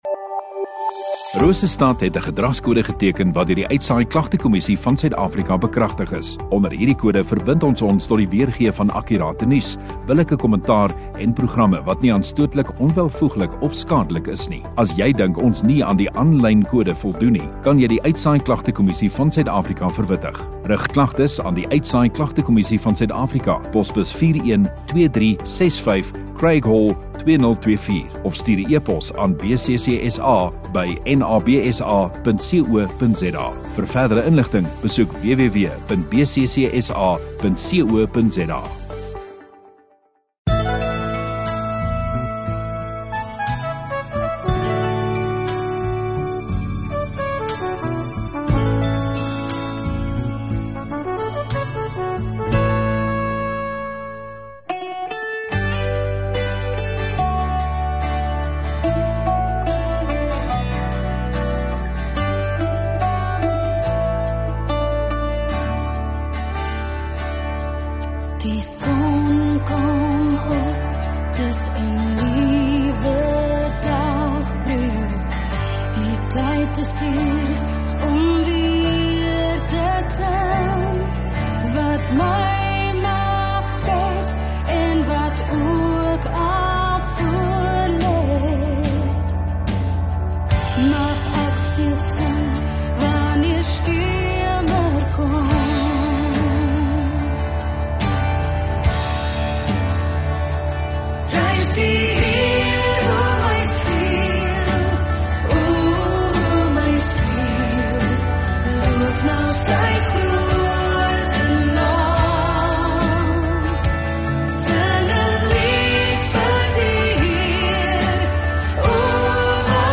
17 Mar Sondagaand Erediens